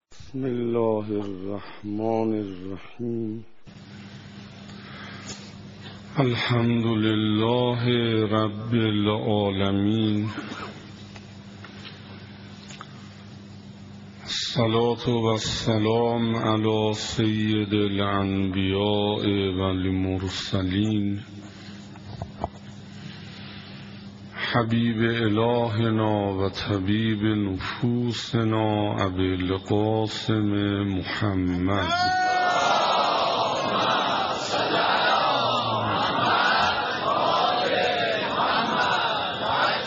سخنراني دوم